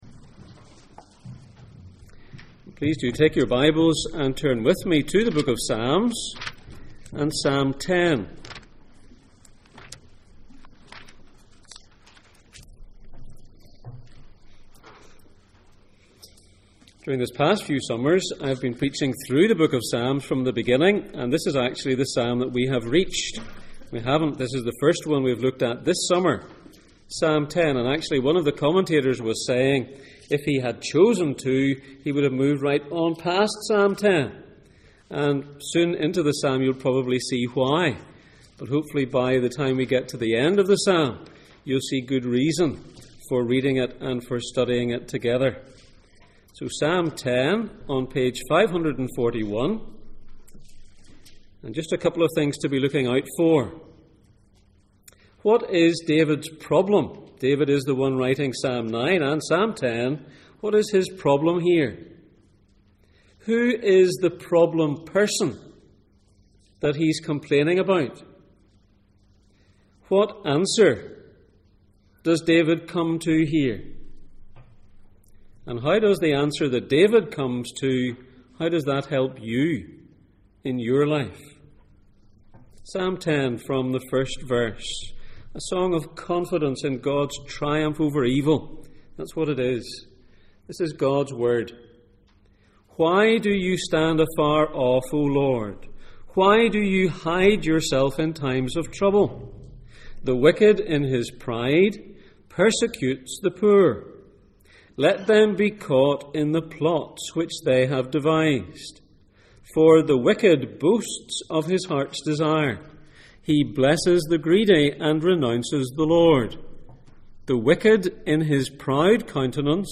Passage: Psalm 10:1-18 Service Type: Sunday Morning %todo_render% « Joy and Forgiveness When the foundations are destroyed… what can the righteous do?